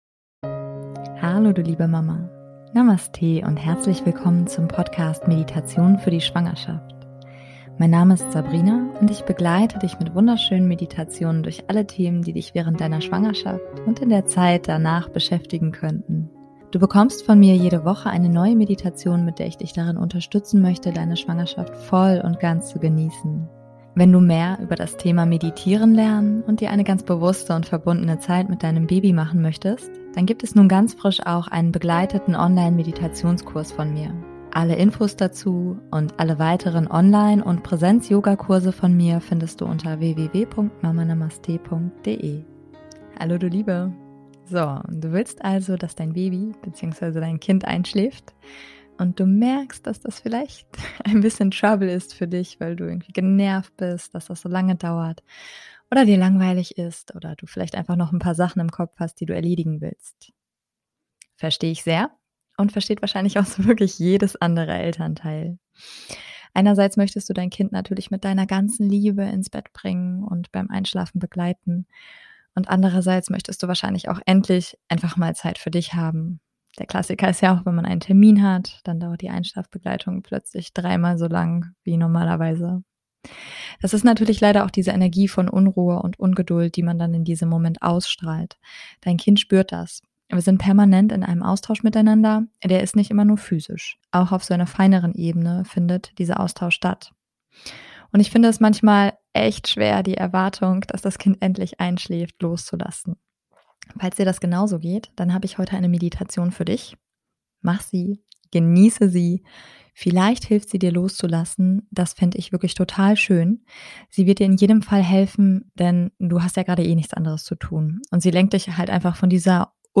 #103 - Meditation zur Einschlafbegleitung deines Babys/Kindes ~ Meditationen für die Schwangerschaft und Geburt - mama.namaste Podcast
DIe Meditation ist so konzipiert, dass du selbstständig für dich weiter meditieren kannst.